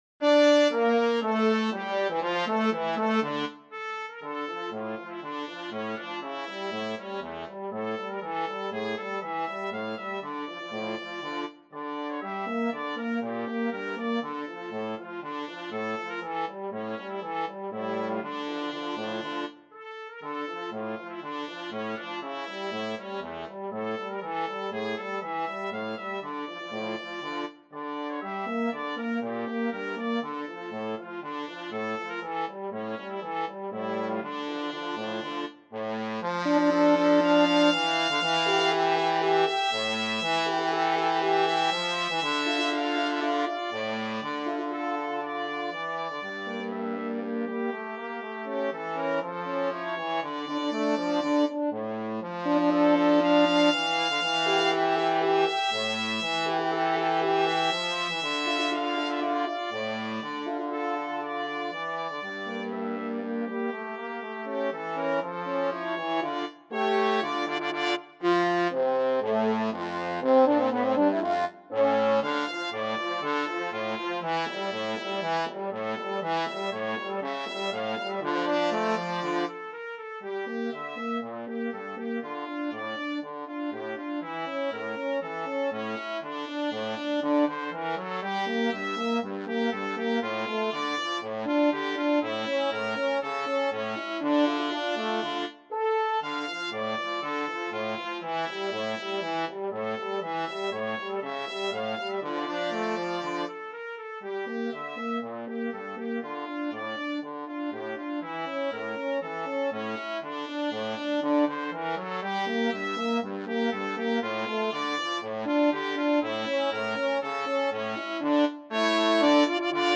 Trumpet
French Horn
Trombone
2/2 (View more 2/2 Music)
Allegro = c.120 (View more music marked Allegro)
Russian